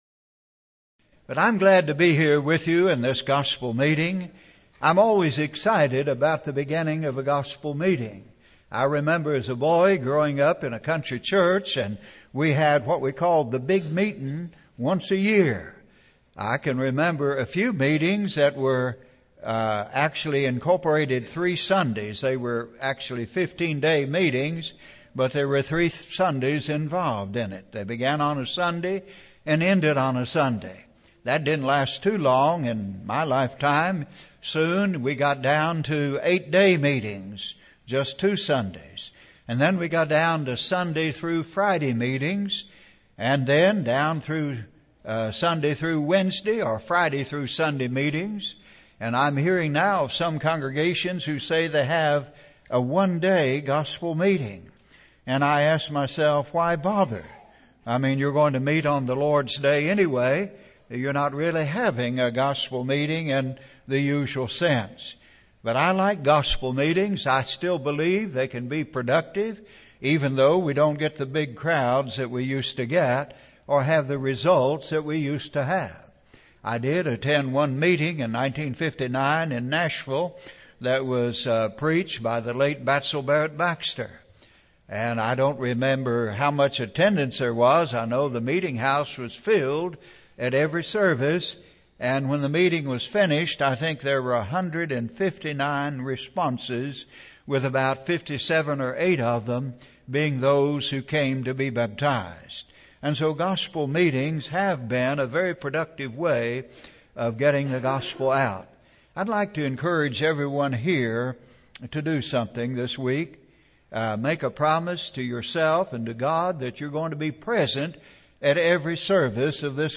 Spring Meeting Service Type: Gospel Meeting « Can I Recommend My Religion?